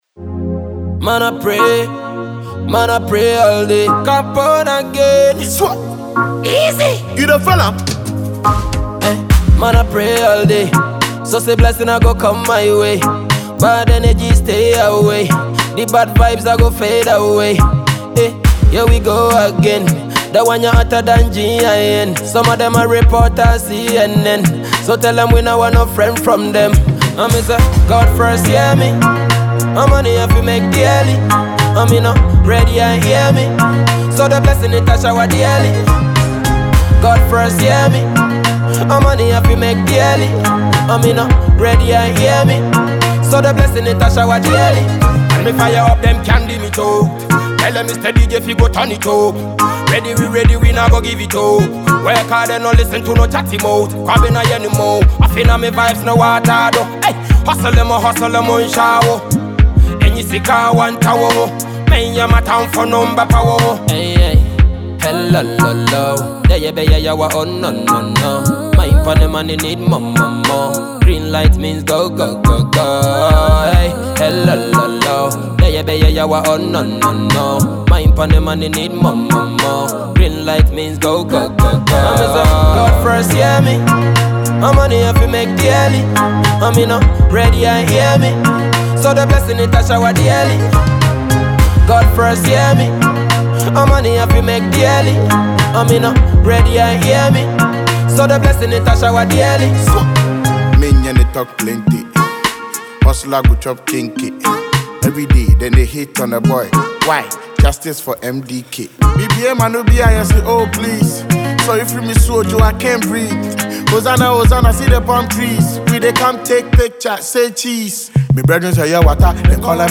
Dancehall artist